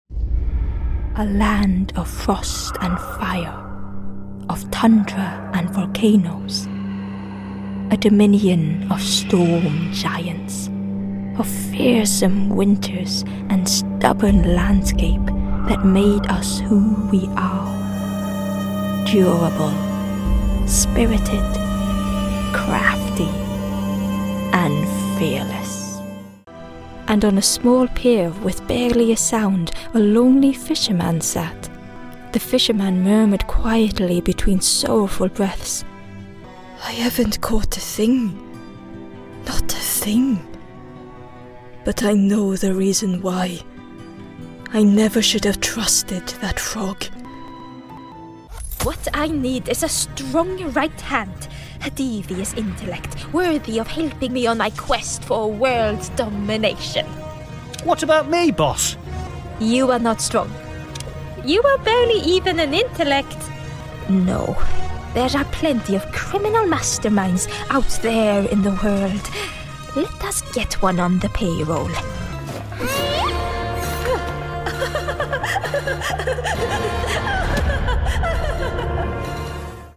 Character Reel - Accented English
I'm a highly experienced Scandinavian voice artist with a fully equipped home studio in London.
English-Character-Reel_Chatterbox.mp3